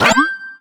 collect_item_19.wav